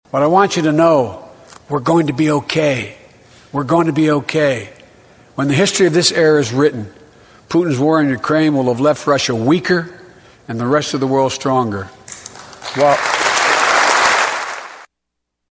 From the Russian invasion of Ukraine to a host of domestic issues, President Joe Biden spent over an hour giving his status report and vision for the future during his initial State of the Union address Tuesday.
0720-biden-weaker-russia.mp3